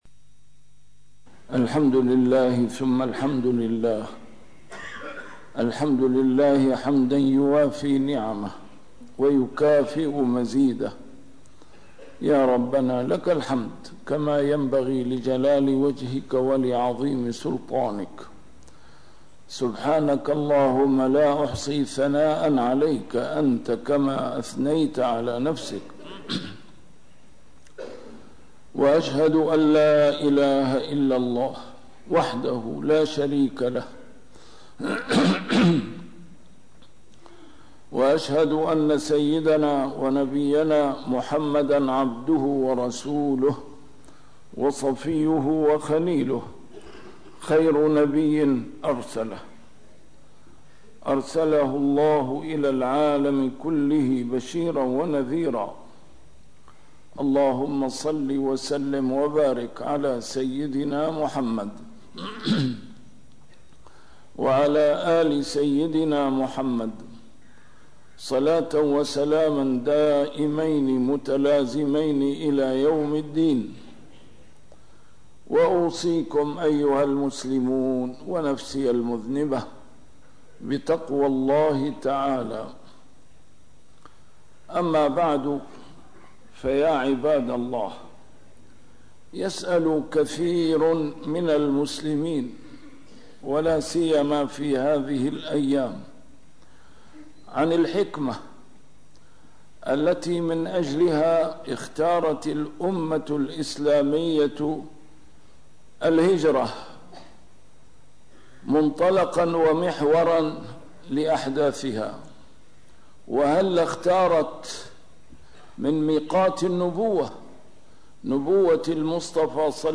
A MARTYR SCHOLAR: IMAM MUHAMMAD SAEED RAMADAN AL-BOUTI - الخطب - الهجرة